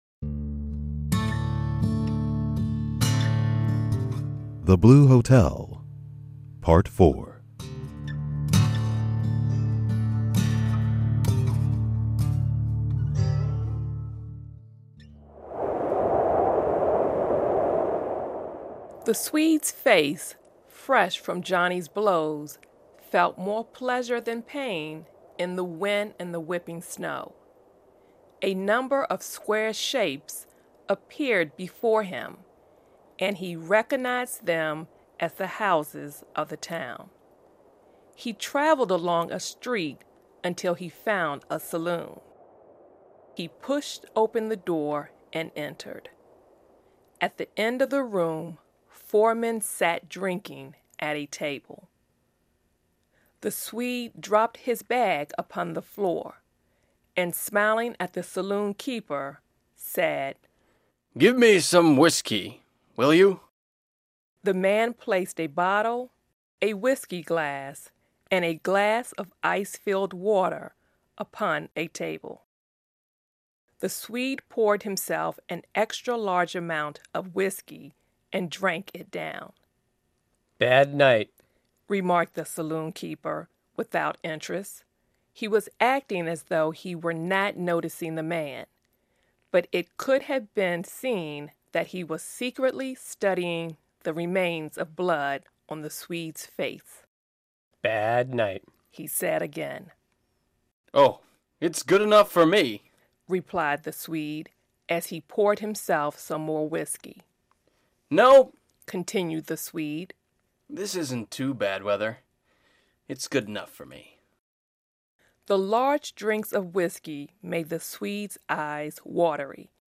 We present the fourth of four parts of the short story "The Blue Hotel," by Stephen Crane.
The audio was recorded and produce by VOA Learning English.